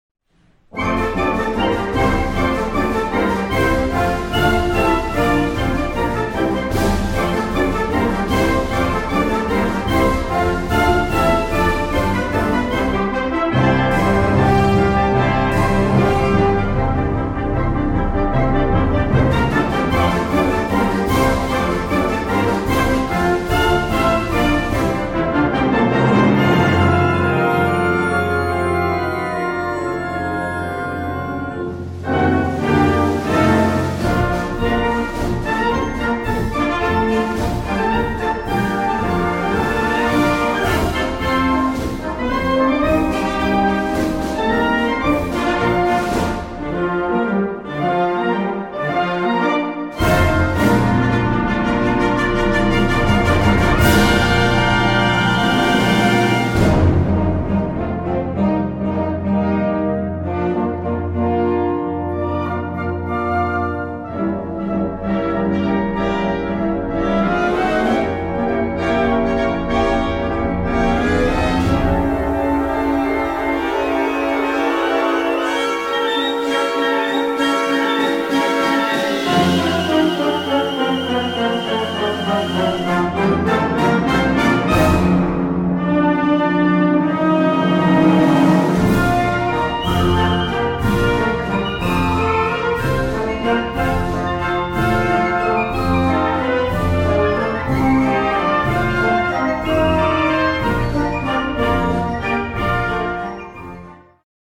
Gattung: Operette
Besetzung: Blasorchester